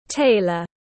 Thợ may tiếng anh gọi là tailor, phiên âm tiếng anh đọc là /ˈteɪlər/.
Tailor /ˈteɪlər/